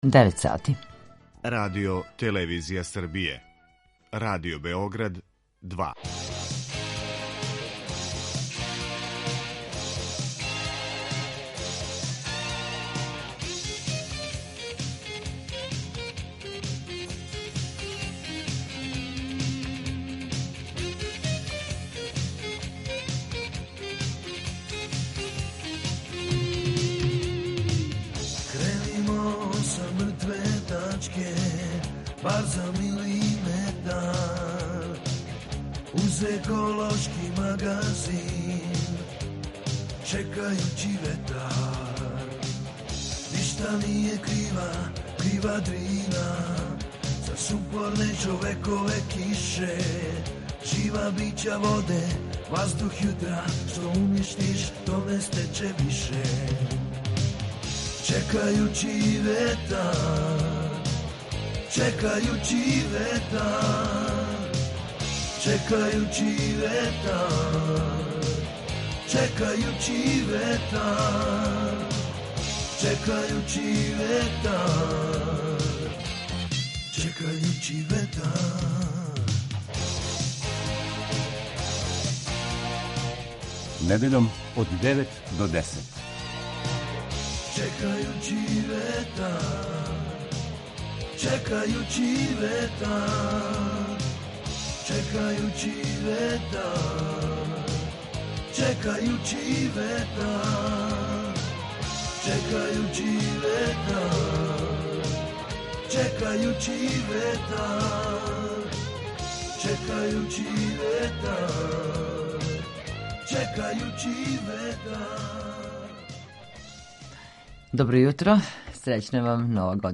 Чућете три награђена тима: Меда са санте леда са Филозофског факултета у Београду, Дивергент са Академије техничко-васпитачких струковних студија (одсек Врање) и Eco Footprints са исте Академије (одсек Ниш).